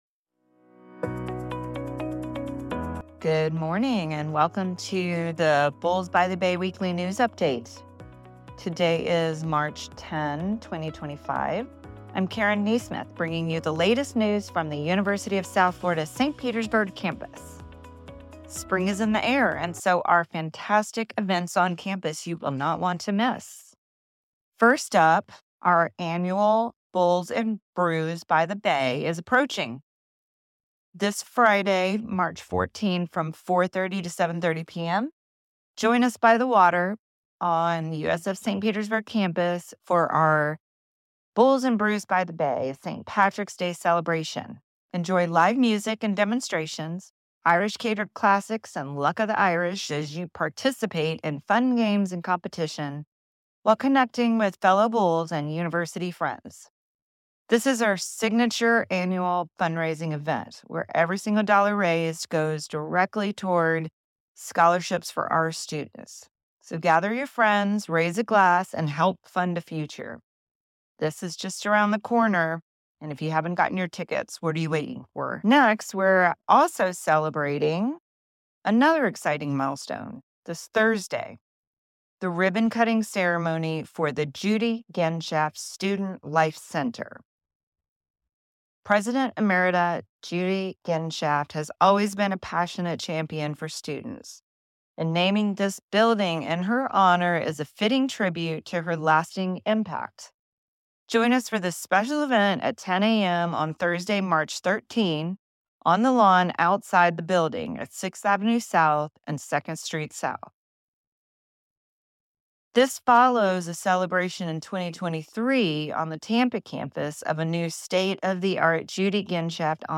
news update